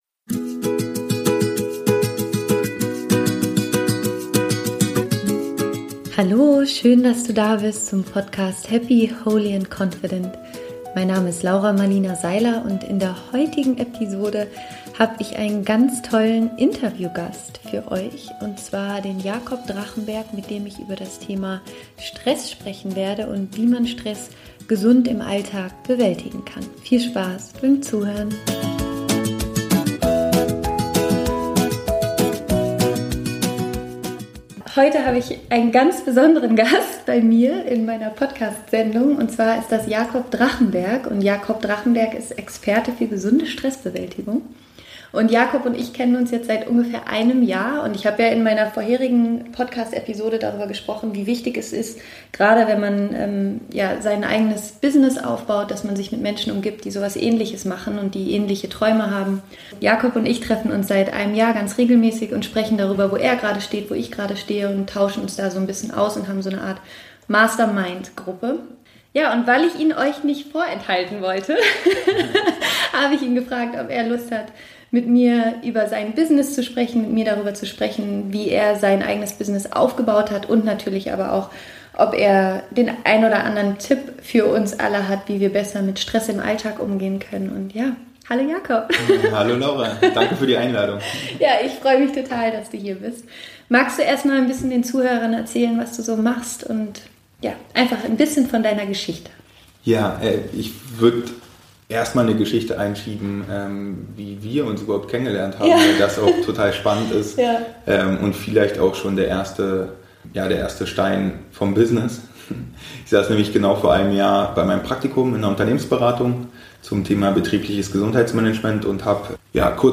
In diesem Interview gibt er seine besten Tipps, wie du deinen "Magic Spot" finden kannst.